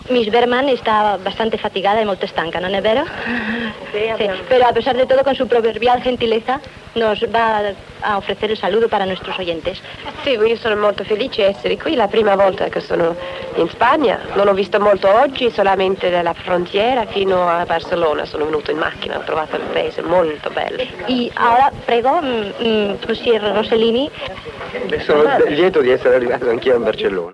Fragment de l'entrevista a l'actriu Ingrid Bergman que va interpretar a principis del mes de gener de 1955 "Jeanne d'Arc au Bucher" en el Gran Teatre del Liceu.